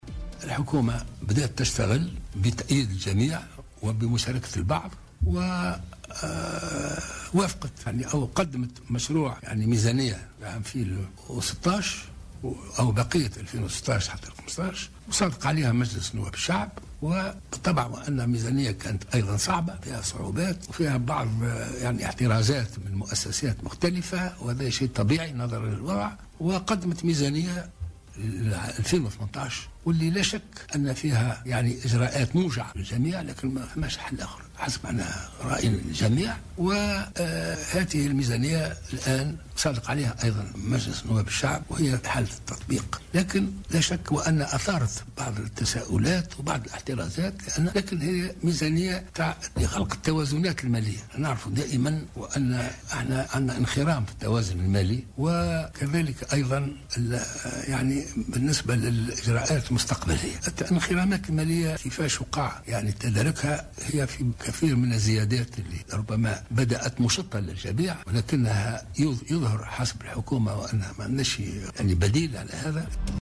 وأضاف خلال اجتماع الأحزاب والمنظمات الموقعة على وثيقة قرطاج أن هدف هذه الميزانية هو معالجة اختلال التوازنات المالية العامة ، مشيرا إلى أن الزيادة في الأسعار لا بديل عنها.